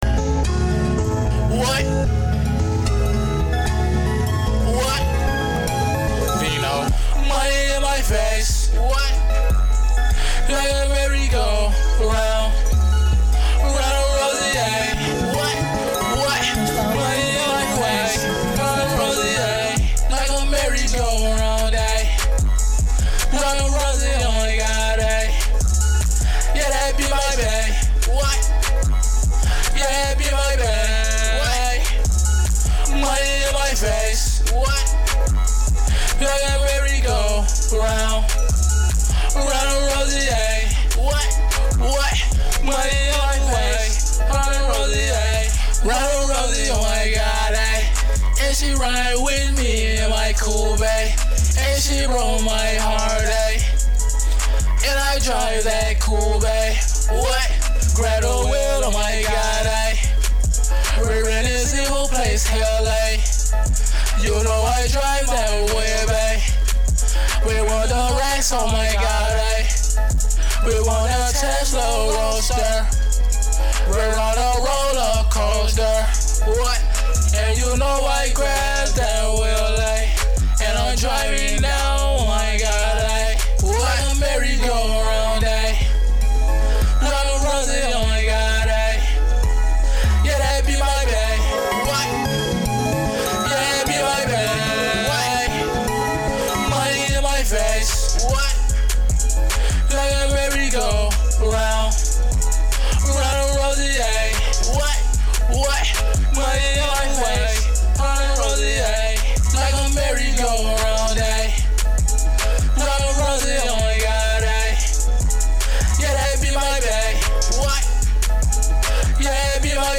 R&B, HipHop, Trap, & Melodic Poject!